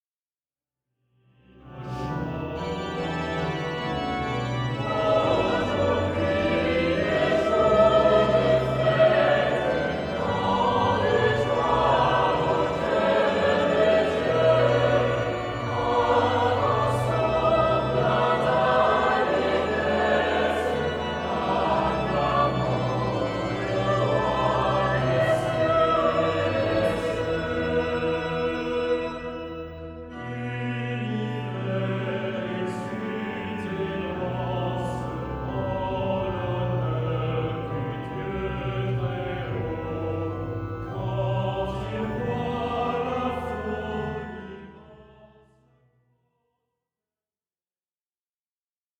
Harmonisations originales de chants d'assemblée